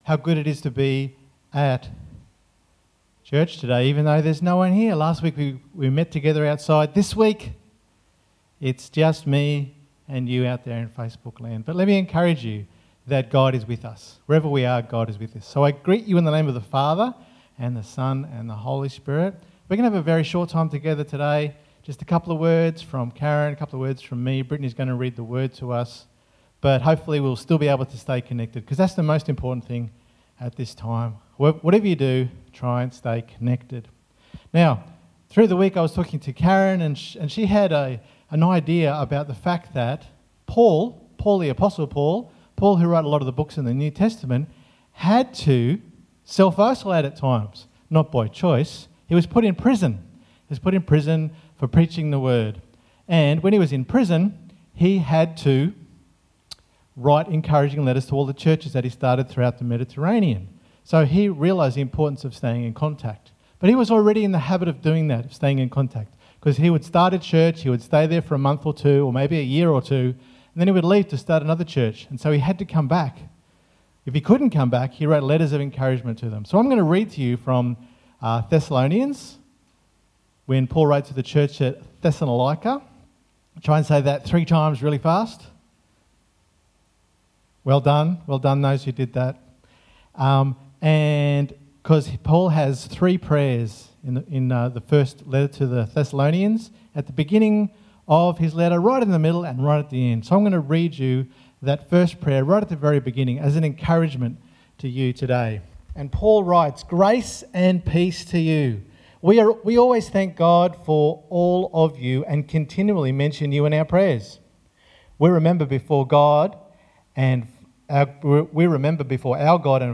New type of service due to Covid 19 This shortened presentation is to keep the Caloundra Salvation Army Community connected.